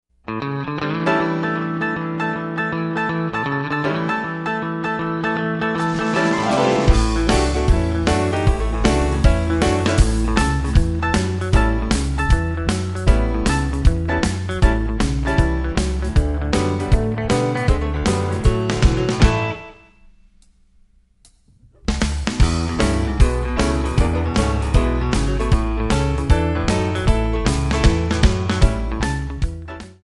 MPEG 1 Layer 3 (Stereo)
Backing track Karaoke
Pop, Jazz/Big Band, 1970s